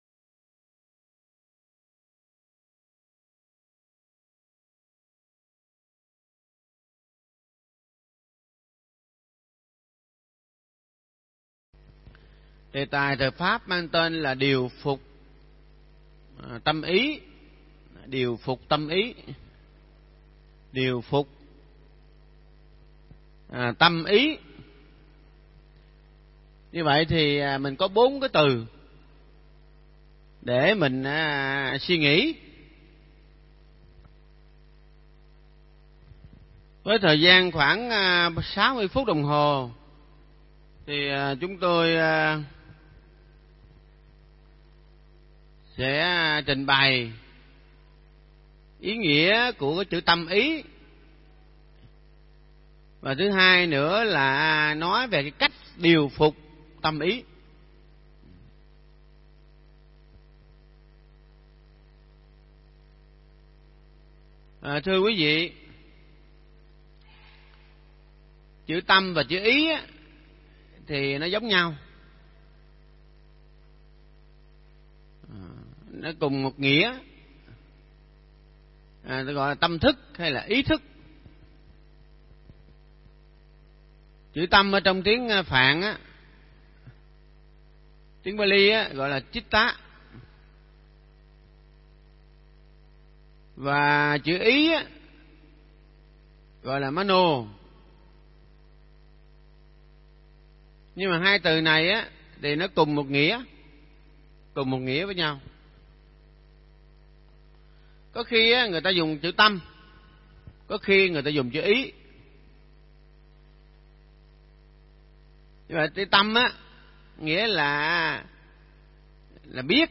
Nghe Mp3 thuyết pháp Điều Phục Tâm Ý
Mp3 pháp thoại Điều Phục Tâm Ý